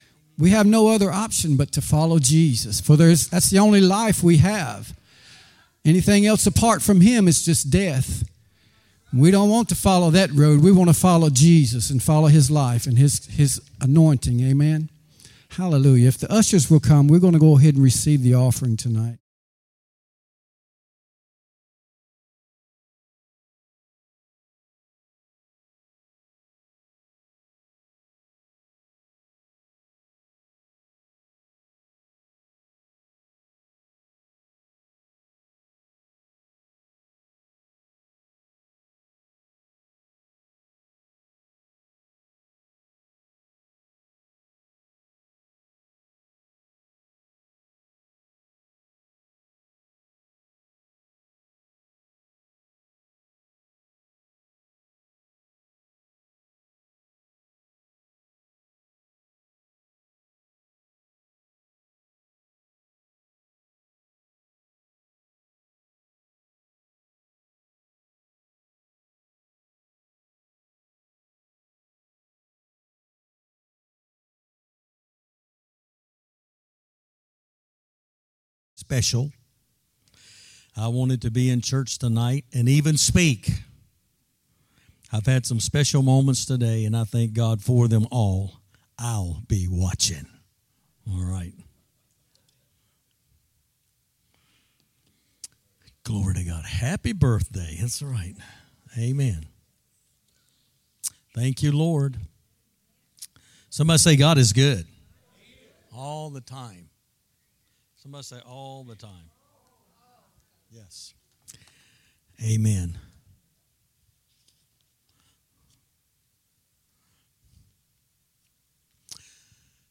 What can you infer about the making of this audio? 1 live-recording 11/13/2024 5:15:21 PM